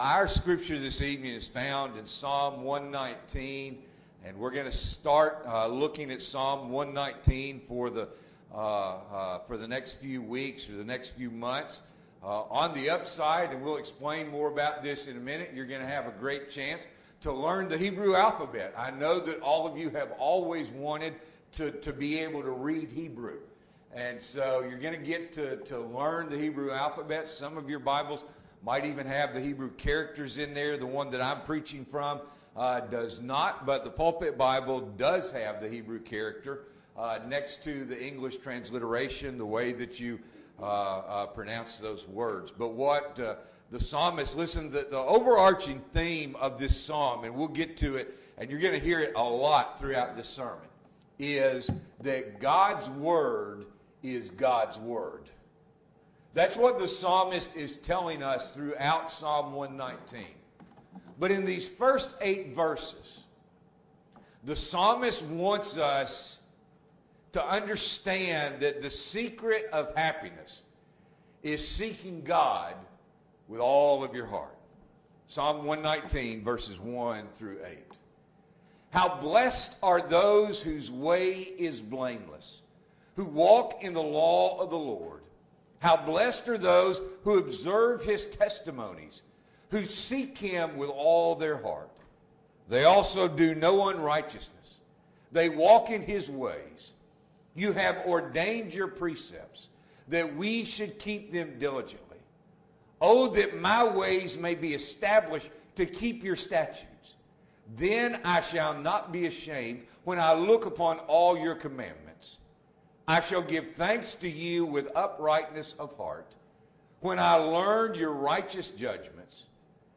February 13, 2022 – Evening Worship